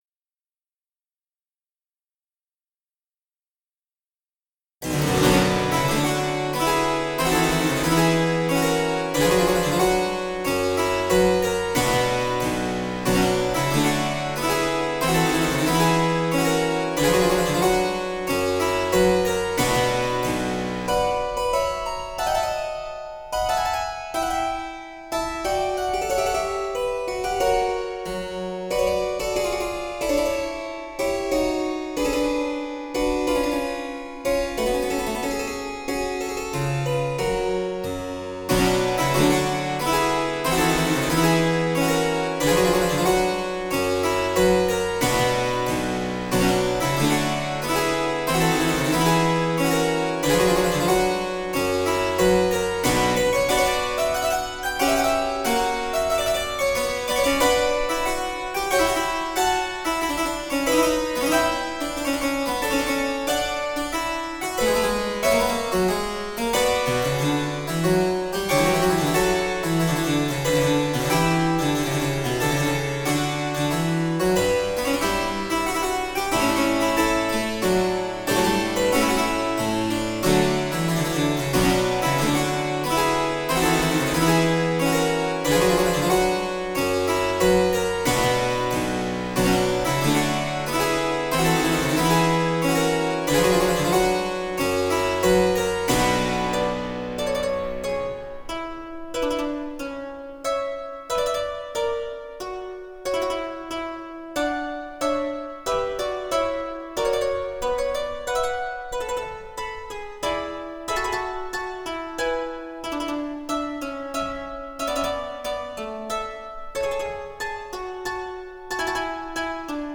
Så här låter våra basso continuo-instrument
Fransk cembalo
Njut av klangen!
- Fracois Couperin Passacaille Cembalo REGISTER.mp3